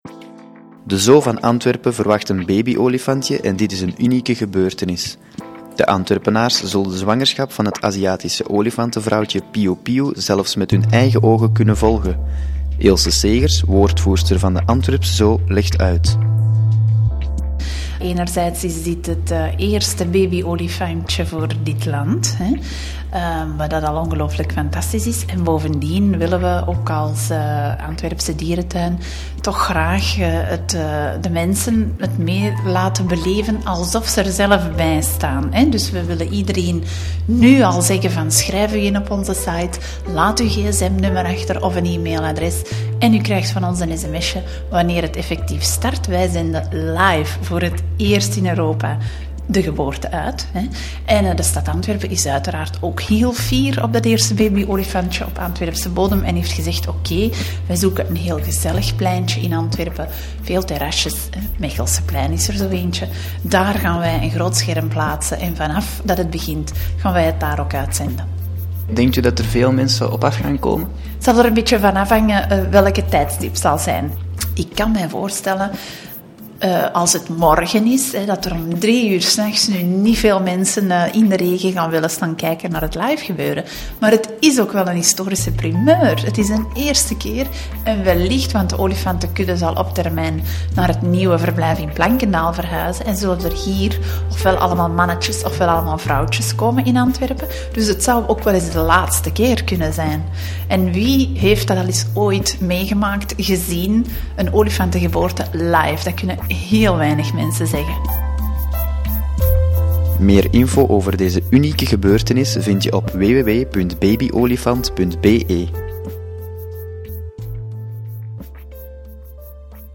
Een gesprek